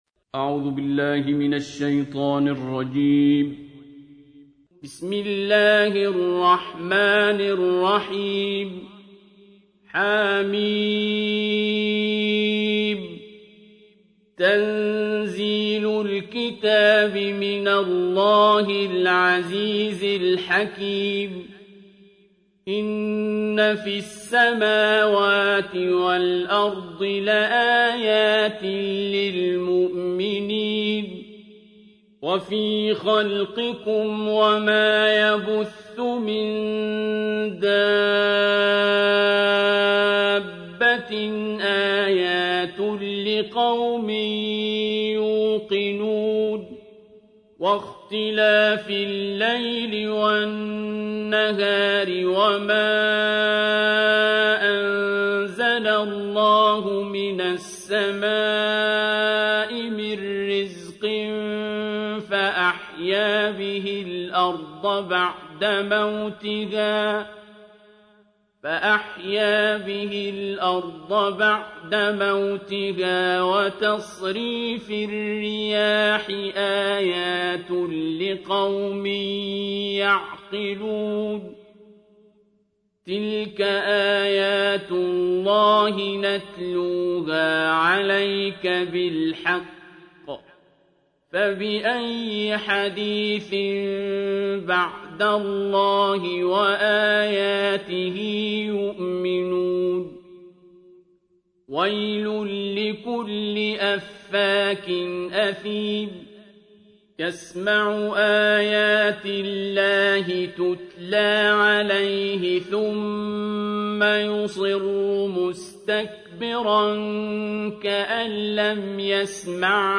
سورة الجاثية | القارئ عبدالباسط عبدالصمد